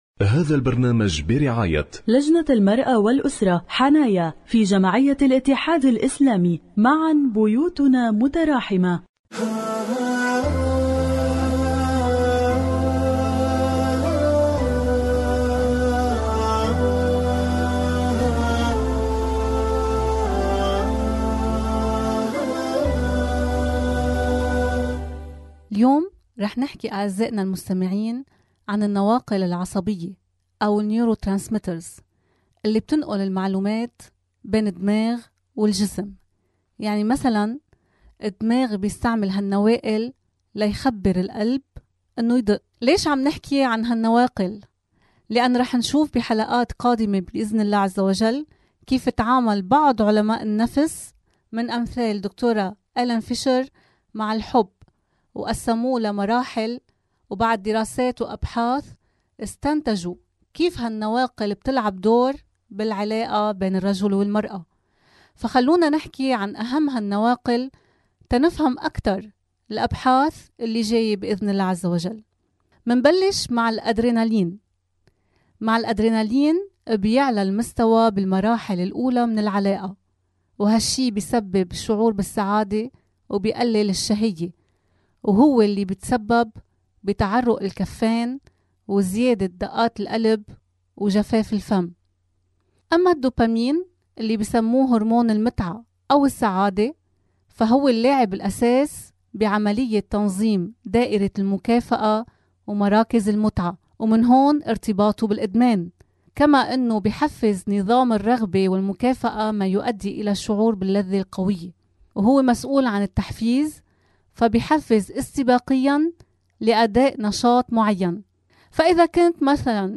برعاية لجنة المرأة والأسرة-حنايا في جمعية الاتحاد الإسلامي على إذاعة الفجر.